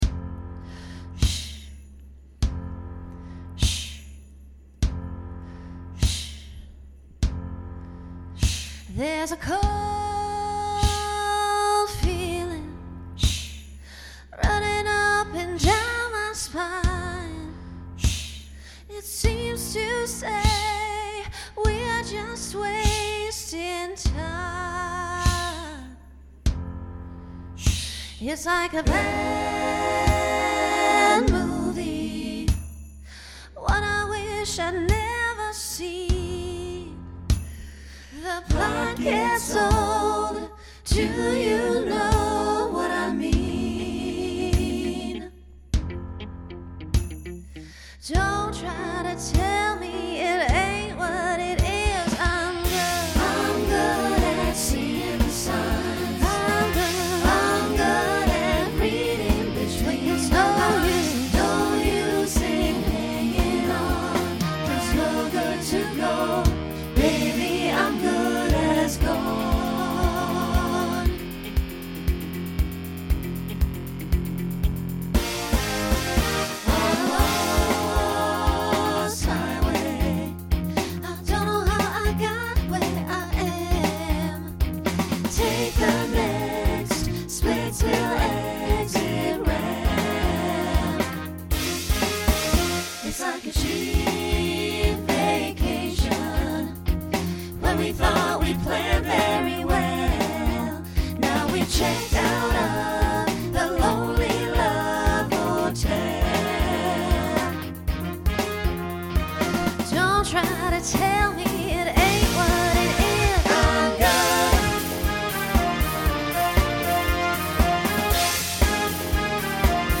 Voicing SATB Instrumental combo Genre Country , Folk , Rock
Mid-tempo